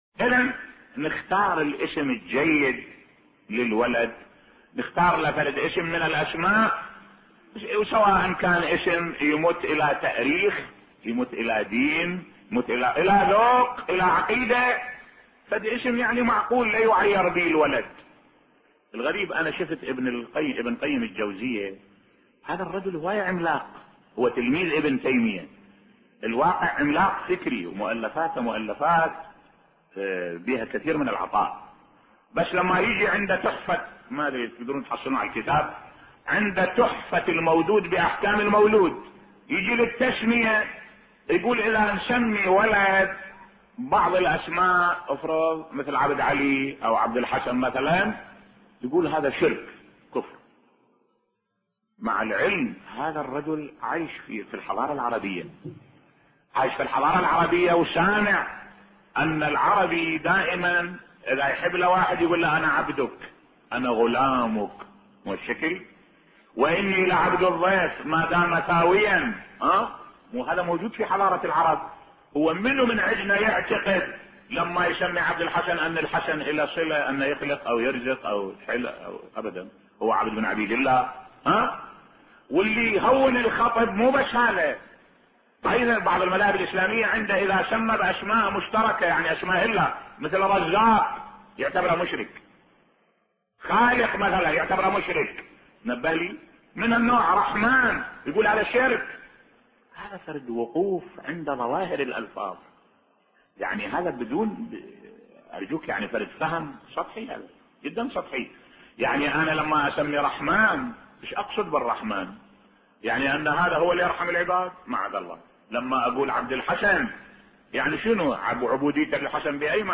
ملف صوتی ابن القيم الجوزوية عملاق فكري لكن لديه شطحات غريبة بصوت الشيخ الدكتور أحمد الوائلي